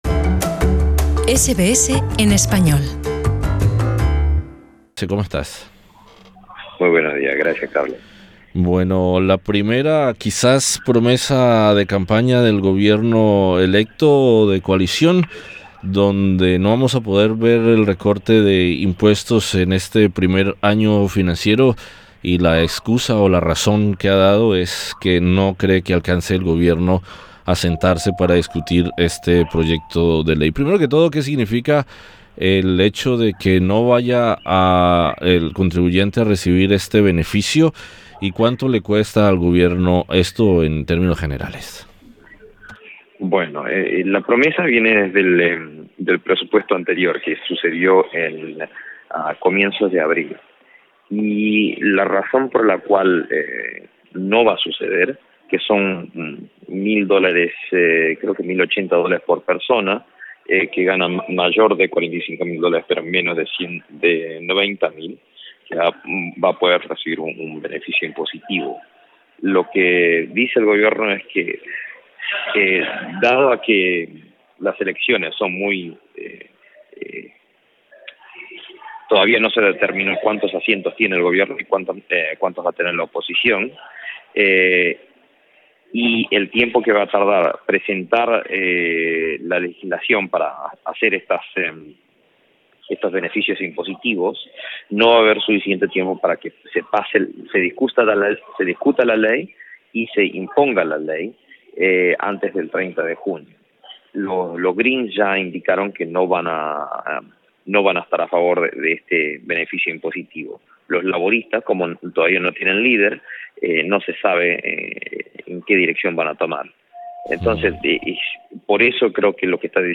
Sobre el impacto de este retraso, o para algunos, primera promesa rota del gobierno, conversamos con el economista en Sídney